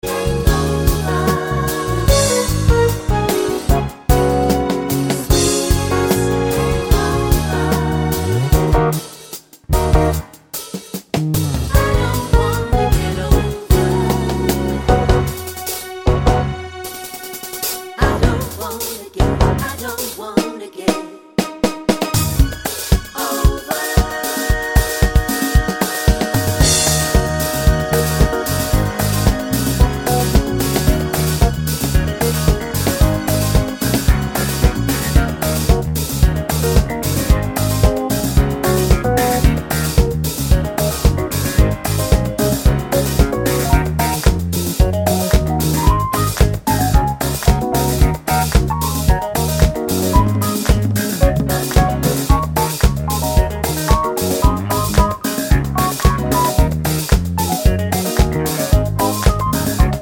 no Backing Vocals Soul / Motown 3:47 Buy £1.50